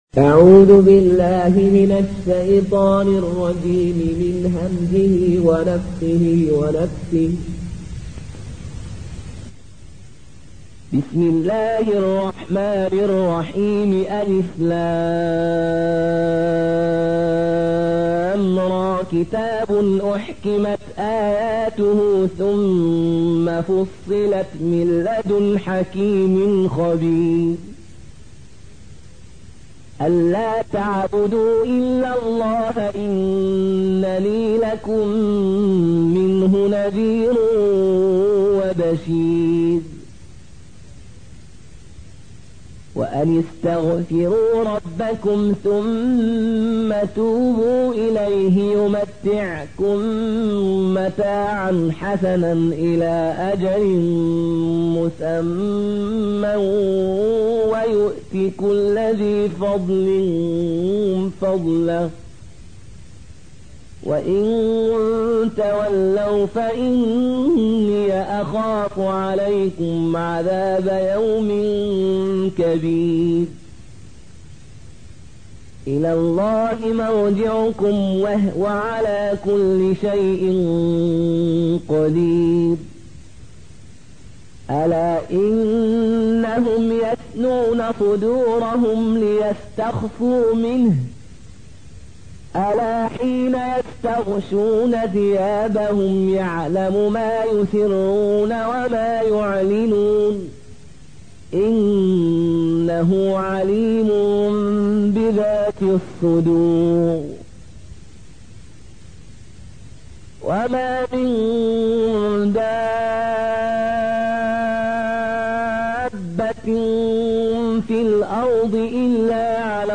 Qaloon an Nafi